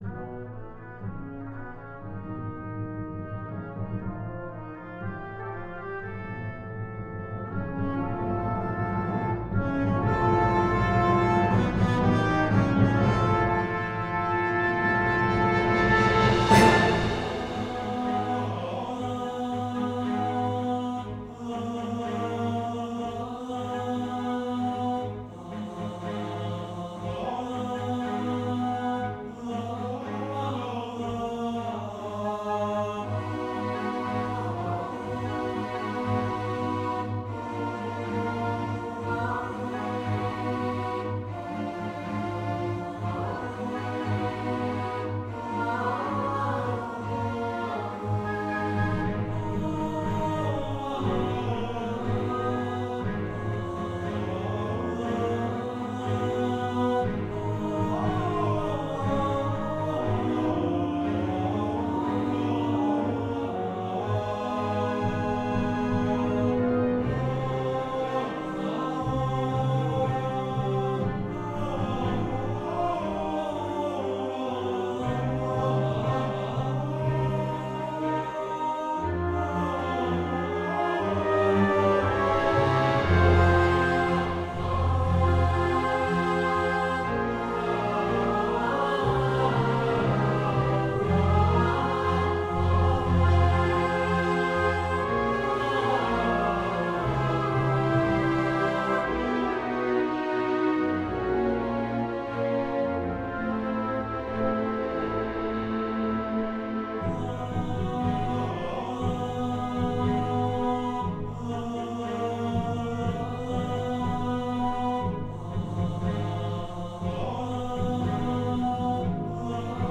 Orchestra audio (con voci sintetiche)